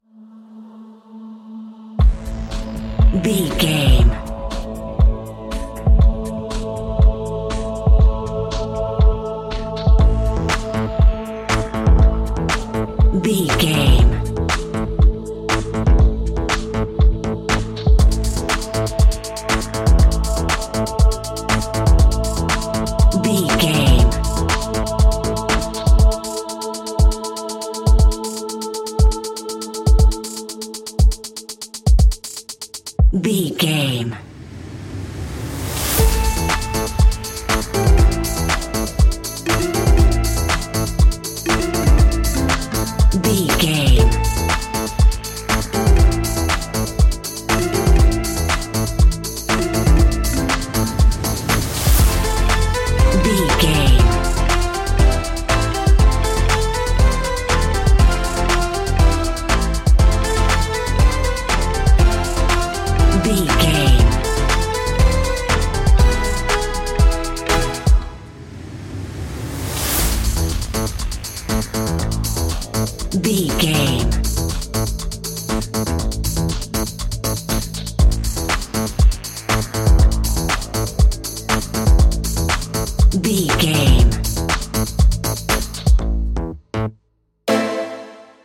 Aeolian/Minor
bright
dreamy
lively
video game
mystical
groovy